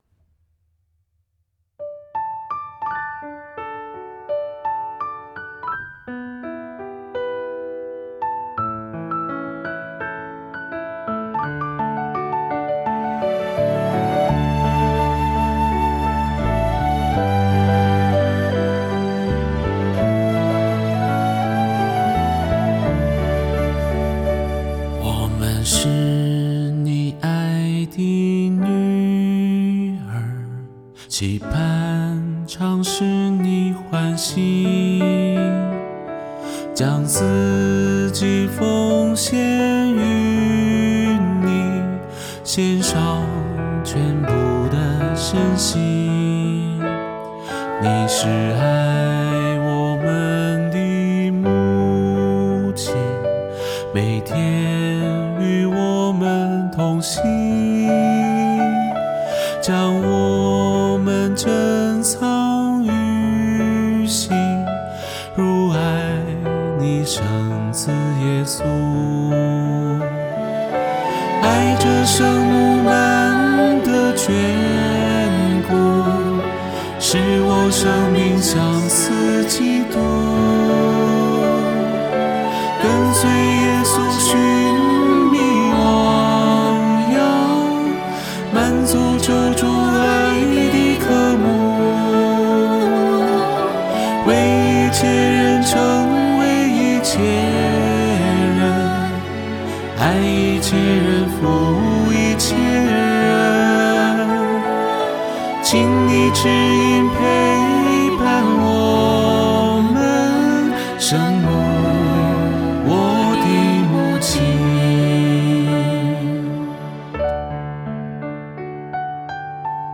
第四步：做一个键盘弹唱的小样发给修女，修女给的回馈是，这首歌既礼仪、又流行、又祈祷。
至此，这首歌就基本可以定下来，开始了后边伴奏制作，和声和演唱等，最终呈现出来的。